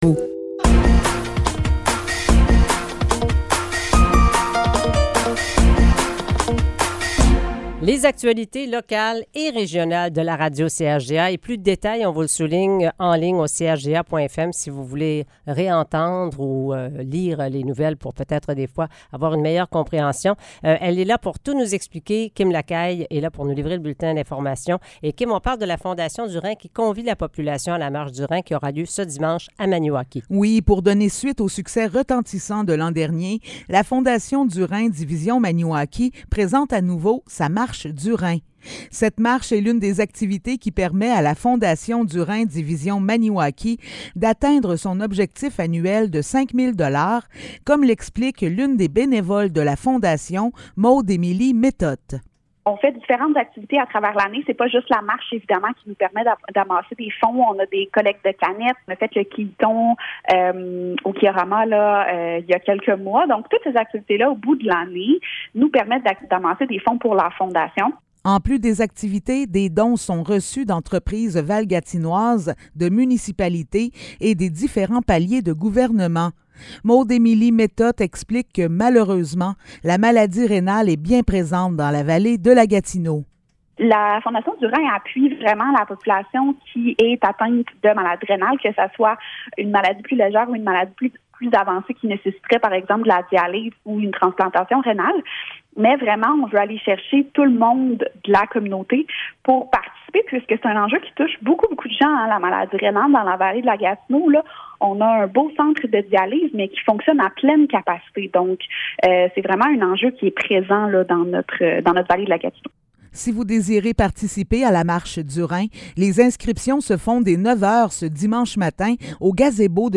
Nouvelles locales - 7 septembre 2023 - 8 h